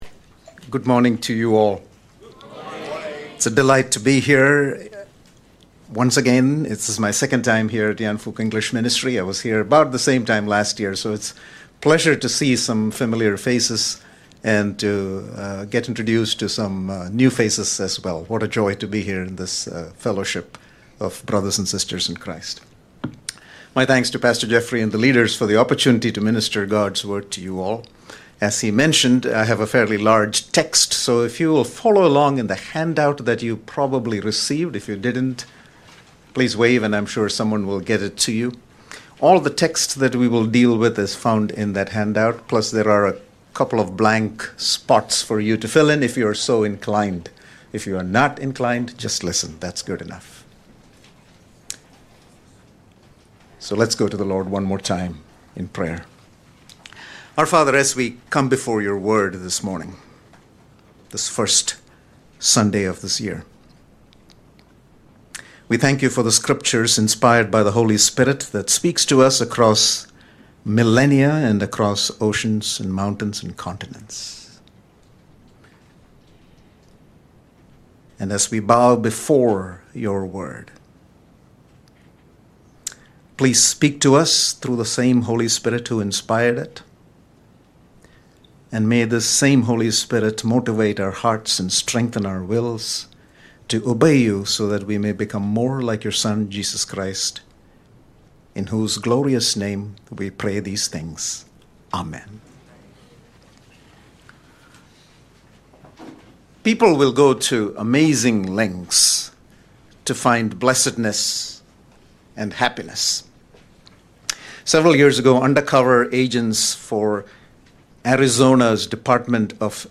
证道集